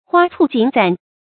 花簇錦攢 注音： ㄏㄨㄚ ㄘㄨˋ ㄐㄧㄣˇ ㄘㄨㄢˊ 讀音讀法： 意思解釋： 形容五色繽紛、繁盛艷麗的景象。